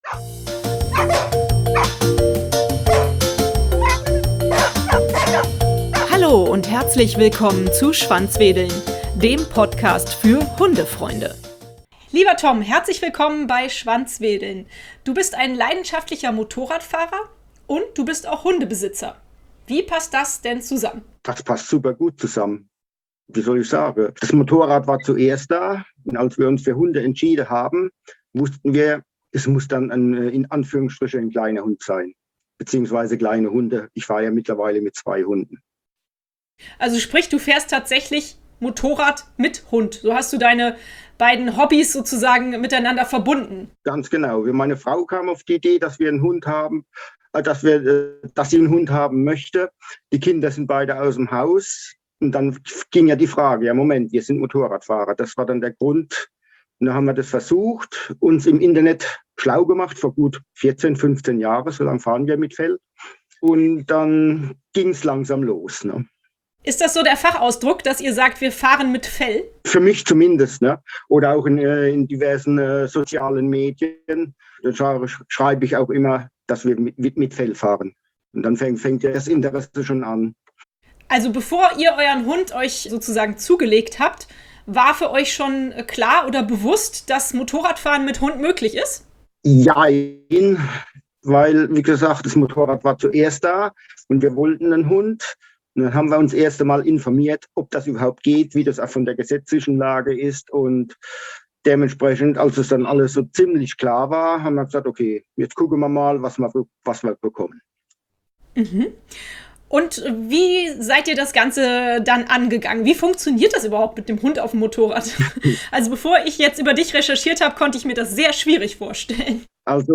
Wenn ihr mehr über das Motorradfahren mit Hund erfahren wollt, hört Euch unbedingt unser Interview zu dem Thema an!